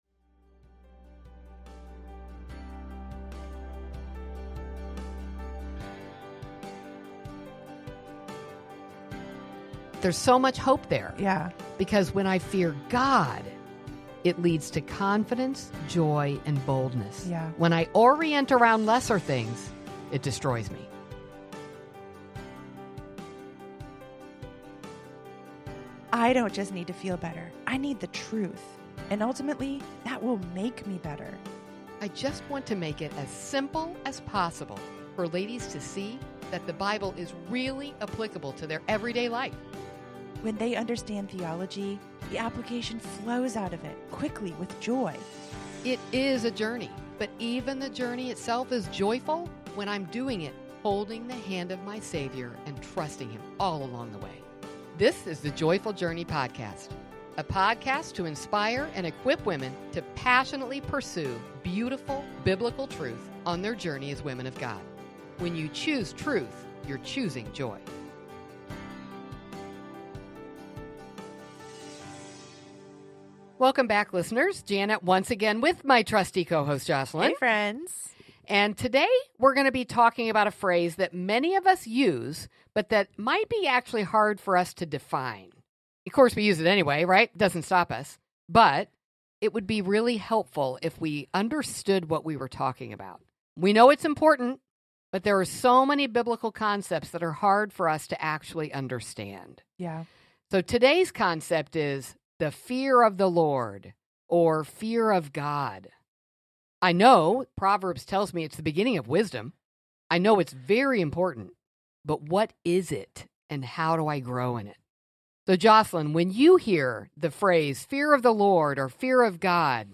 The ladies guide us to understand why fearing the Lord actually leads us to find hope in Him.